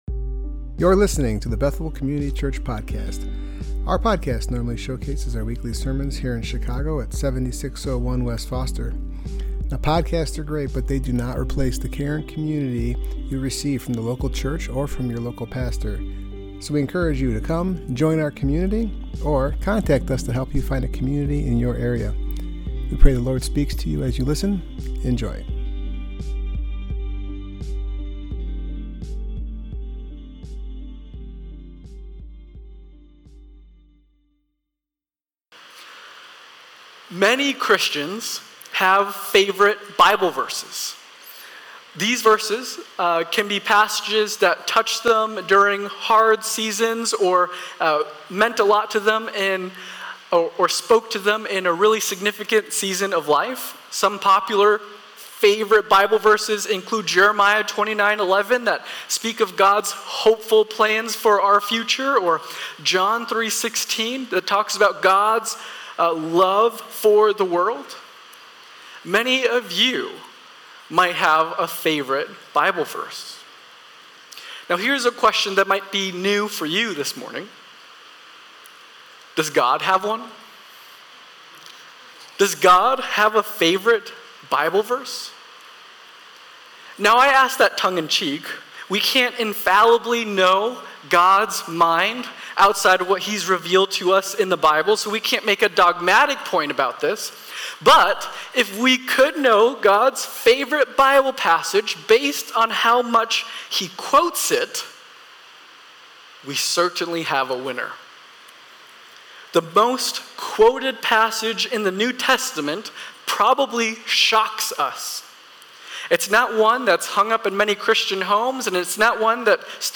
Passage: Psalm 110 Service Type: Worship Gathering « Core Values